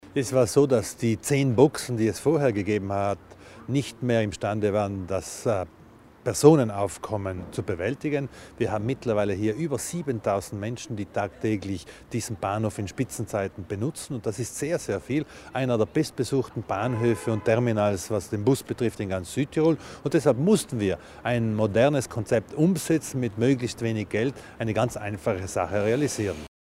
Landesrat Widmann zur Neugestaltung des Busbahnhofs Brixen